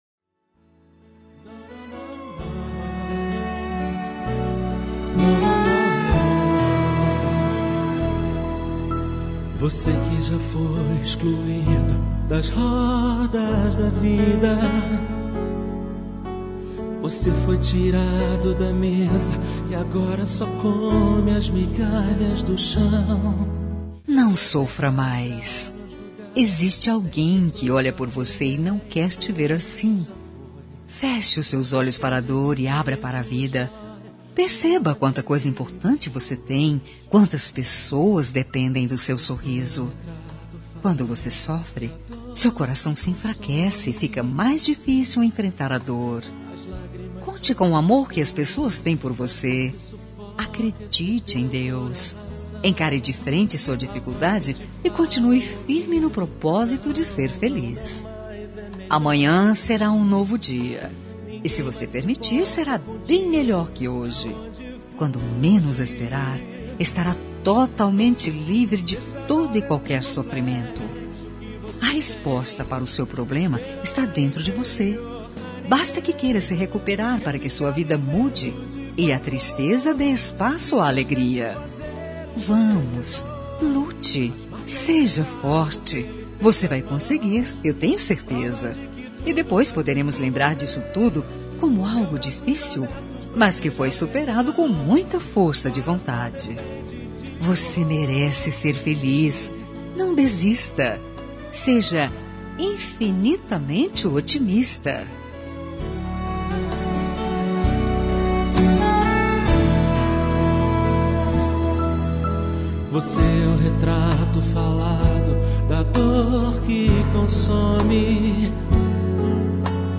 Telemensagem de Otimismo – Voz Feminina – Cód: 182